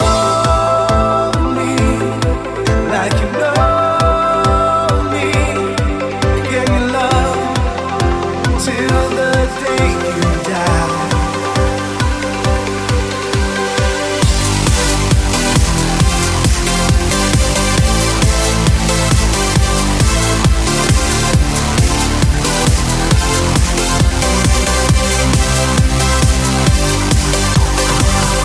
Genere: dance, club, edm, remix